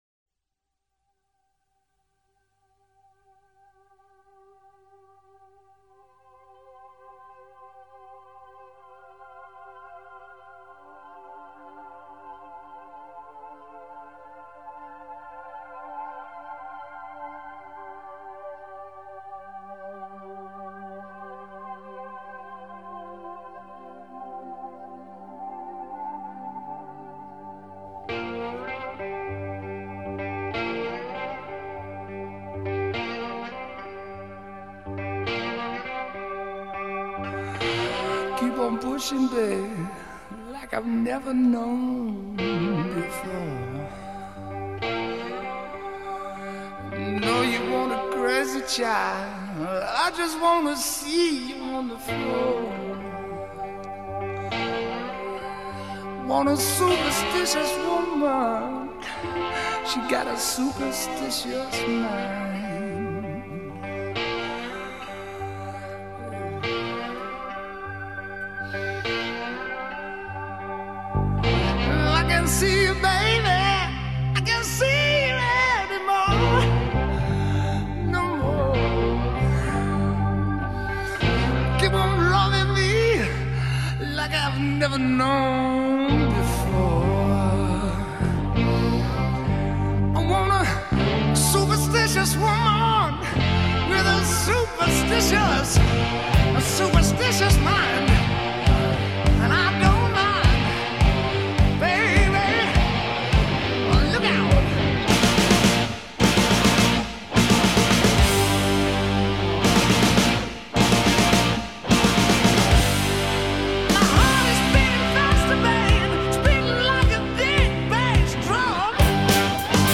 Zeppelin-esque riffs